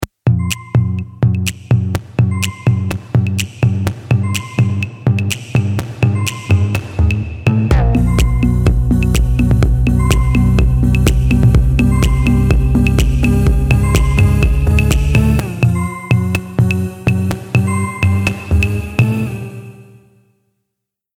| minimal techno |